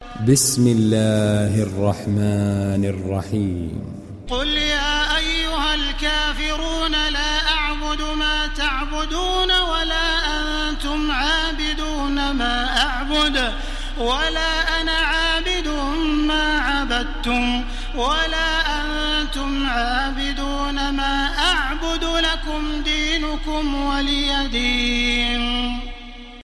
Download Surah Al Kafirun Taraweeh Makkah 1430